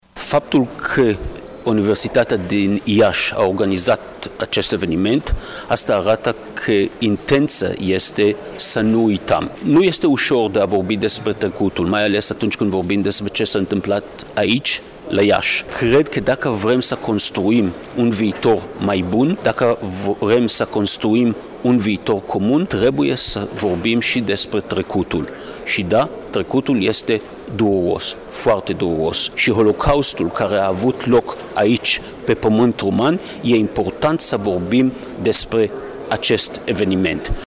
Universitatea „Alexandru Ioan Cuza” din Iaşi găzduieşte în aceste două zile conferinţa internaţională cu tema „Iaşi 1941-2021: Memorie, Asumare, Uitare”.
În cadrul întâlnirilor, Ambasadorul statului Israel, David Saranga, a subliniat faptul că vorbind despre trecutul dureros vom reuşi să contruim un viitor mai bun.